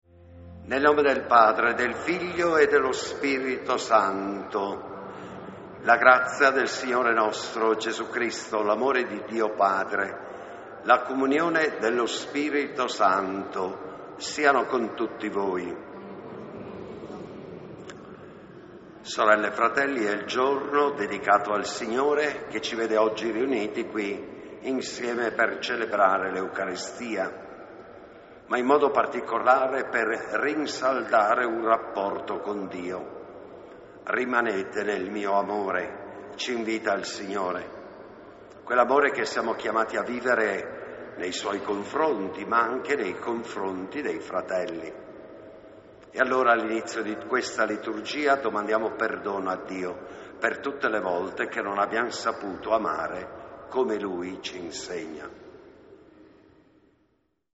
...con un passaggio dal fondo del Duomo...